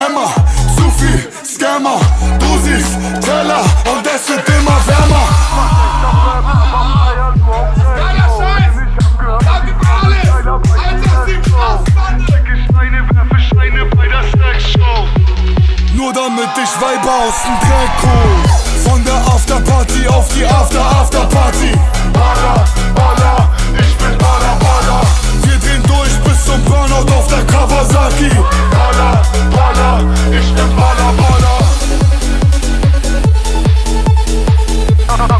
Kategorien Rap